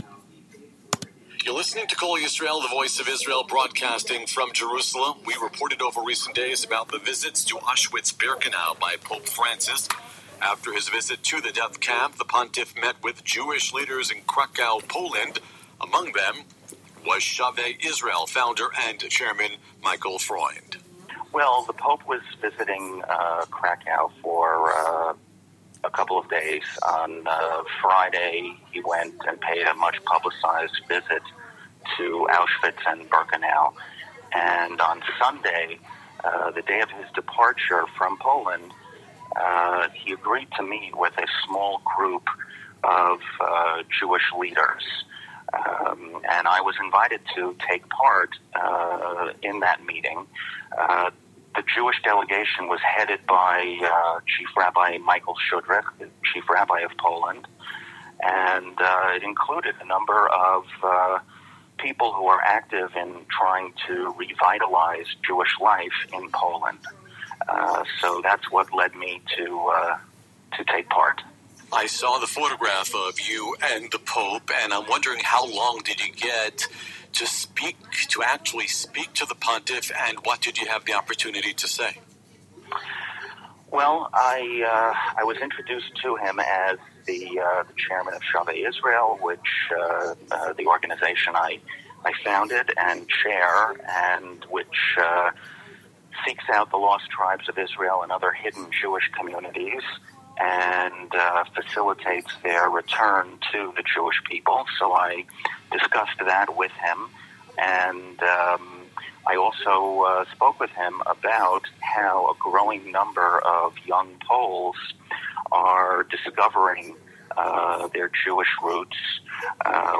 interview-with-iba-english-news-meeting-with-pope.mp3